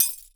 GLASS_Fragment_06_mono.wav